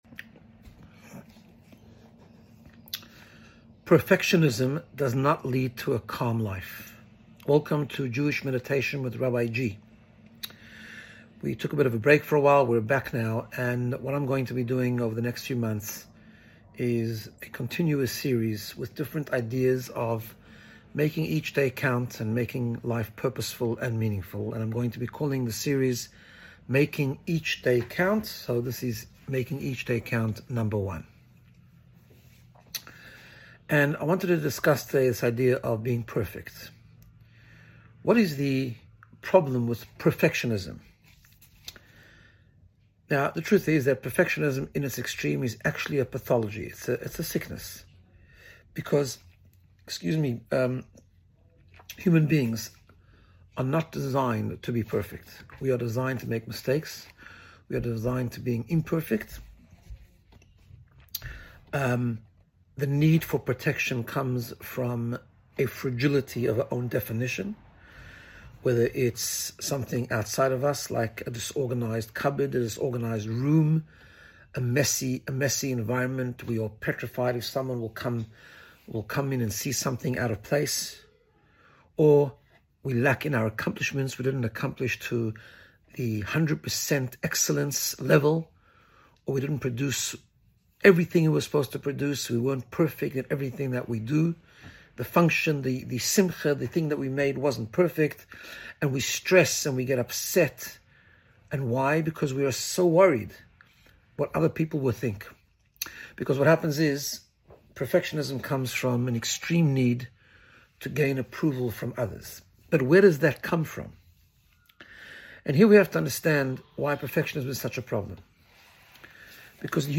Making Each Day Count: Episode 1 - Jewish Meditation